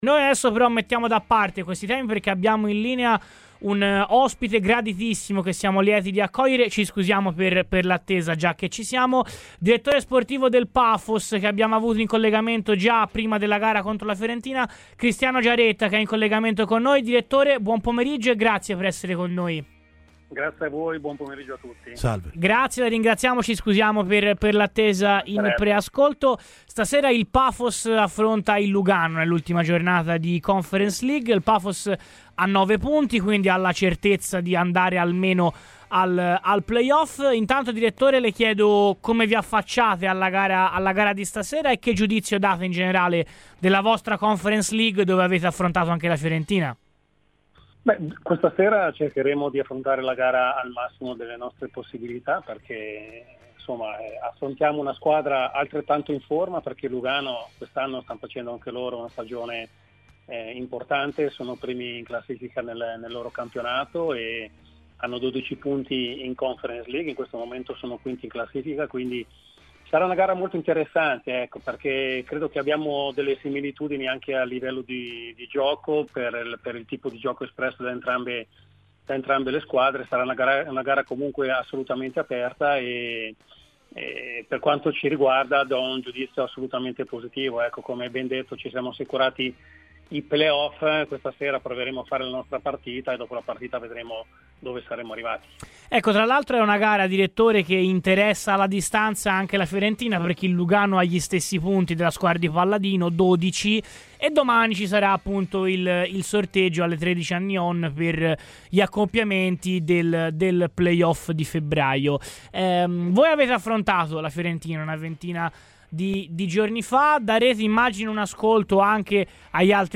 in onda su Radio FirenzeViola.